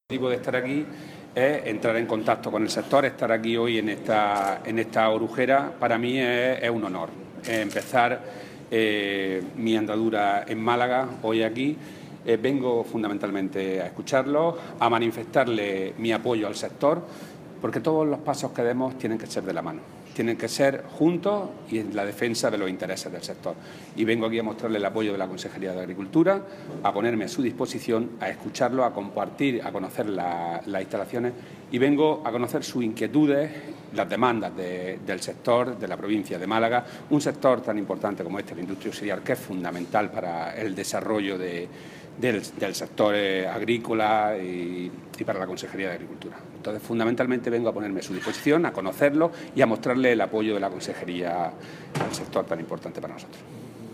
Declaraciones de Rodrigo Sánchez sobre su visita a la cooperativa Orujera Interprovincial Fuente de Piedra